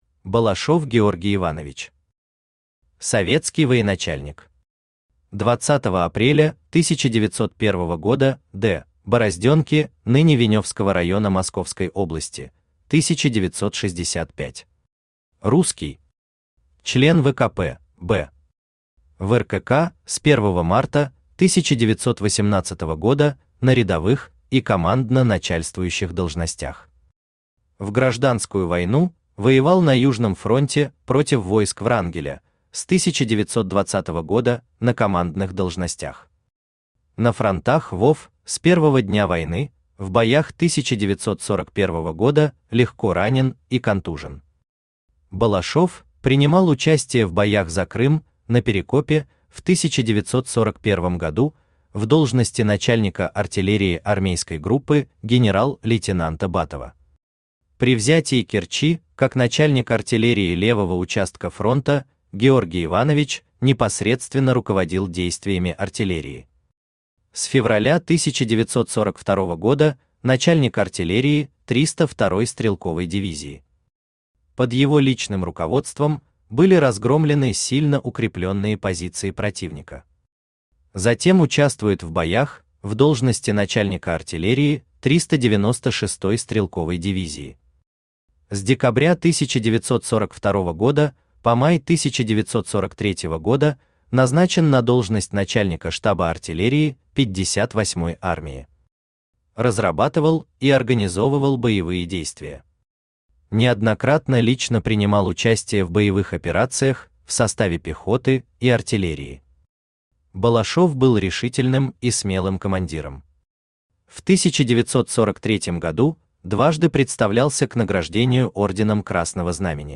Аудиокнига Все генералы Сталина. Артиллерия. Том 2 | Библиотека аудиокниг
Том 2 Автор Денис Соловьев Читает аудиокнигу Авточтец ЛитРес.